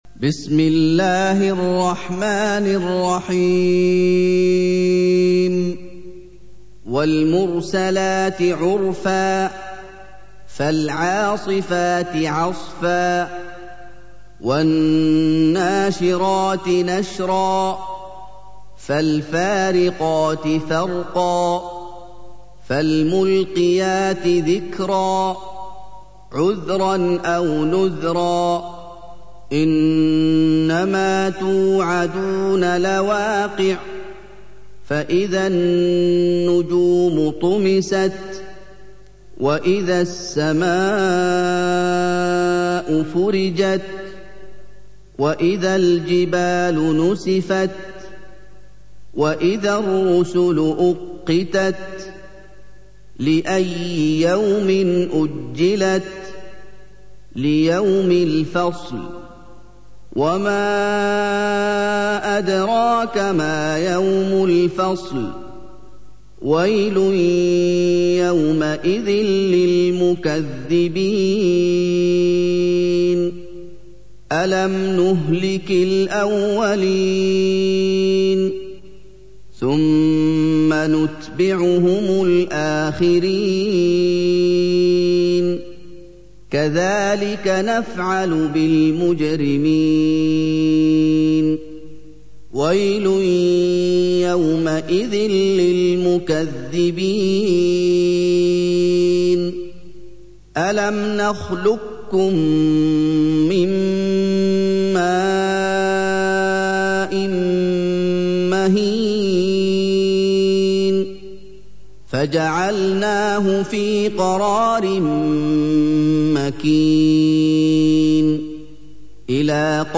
سُورَةُ المُرۡسَلَاتِ بصوت الشيخ محمد ايوب